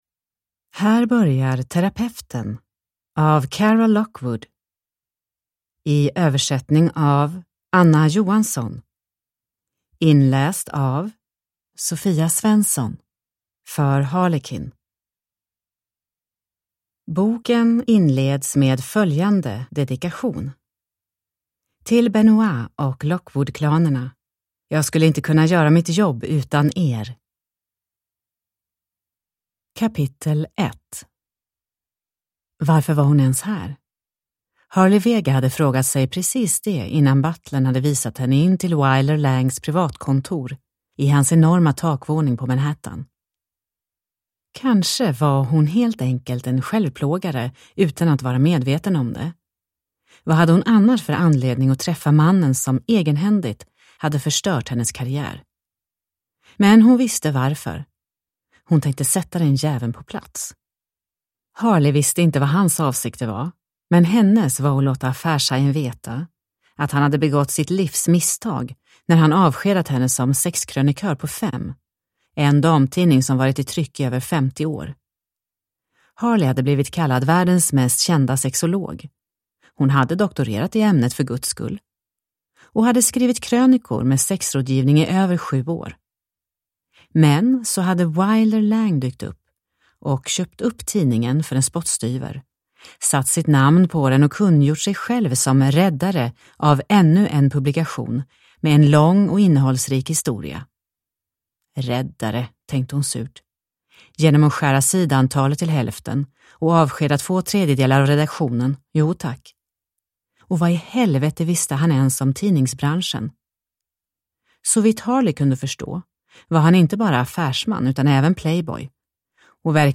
Terapeuten (ljudbok) av Cara Lockwood